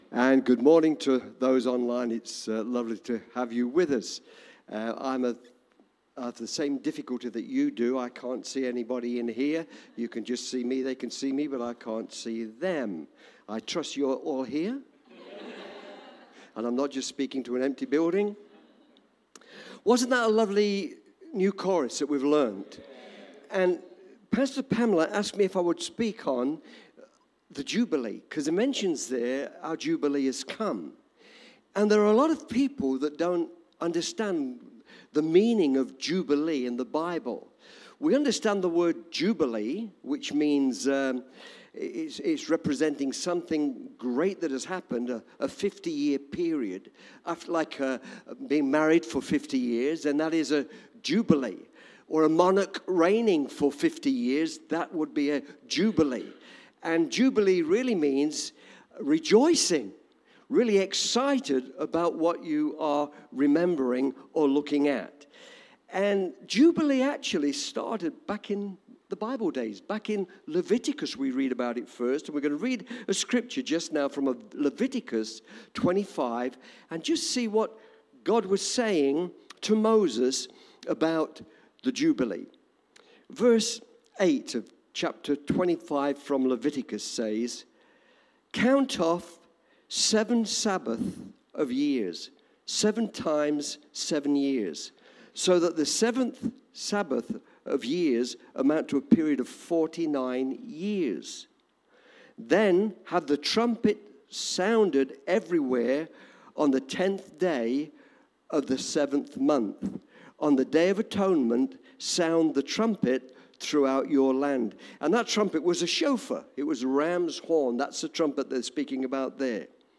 Cityview-Church-Sunday-Service-The-Year-of-Jubilee.mp3